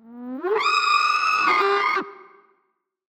affectionate_scream.ogg